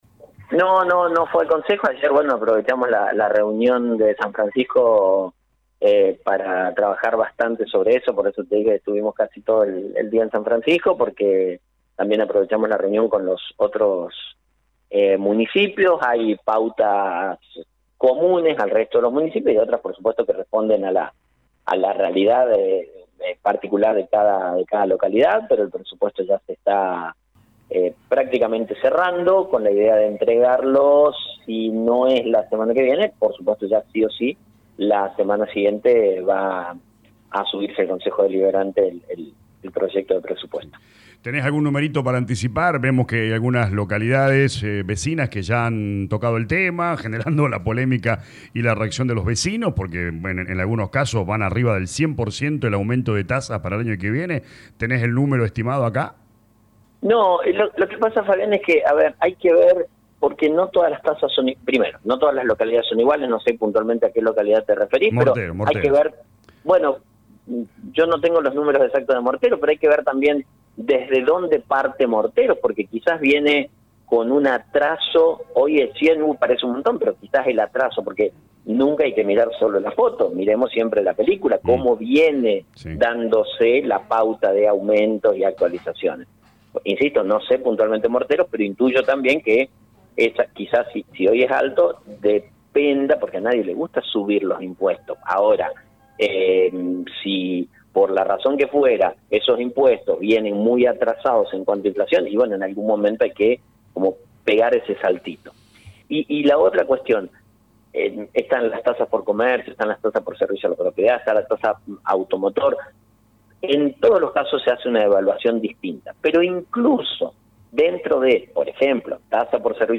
En diálogo con LA RADIO el intendente Actis manifestó que en las últimas semanas mantuvo reuniones con la comisión de relaciones laborales para definir la meta salarial.